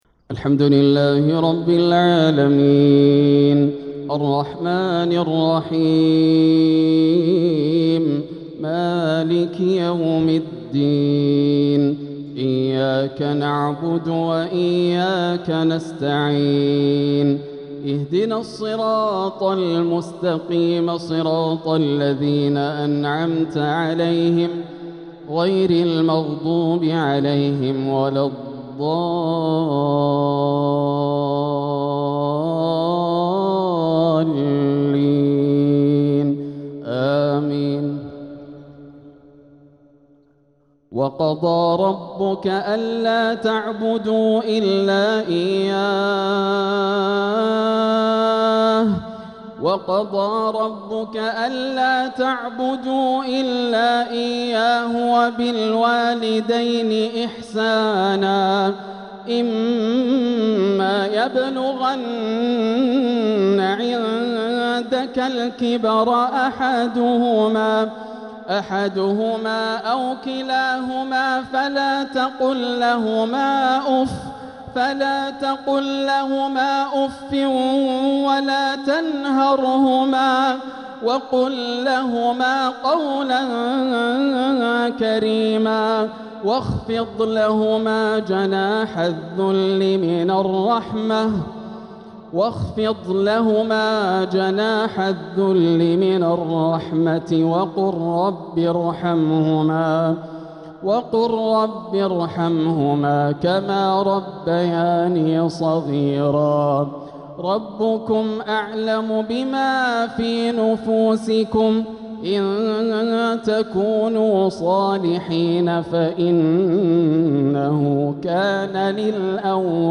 تلاوة من سورتي الإسراء والأحقاف | صلاة الجمعة 23 جمادى الأولى 1447هـ > عام 1447 > الفروض - تلاوات ياسر الدوسري